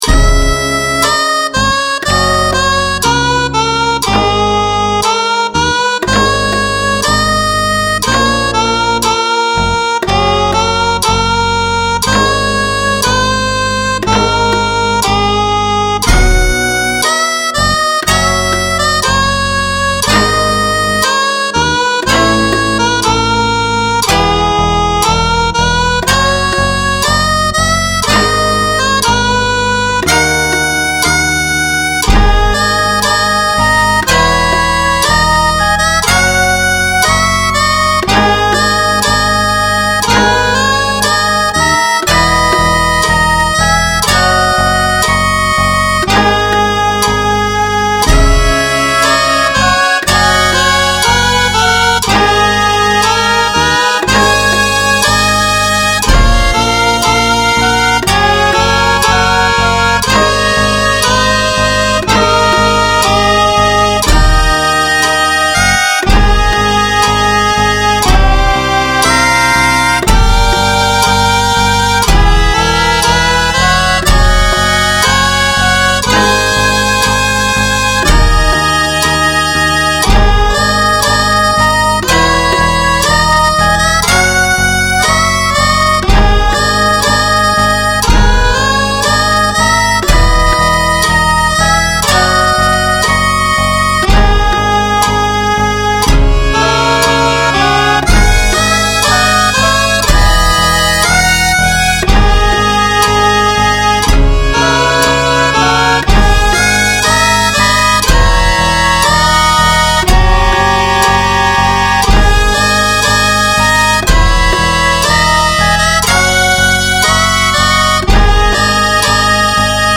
*レン シリーズ（和風な編成）